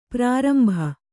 ♪ prārambha